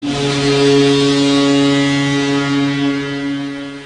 Bruins Goal Horn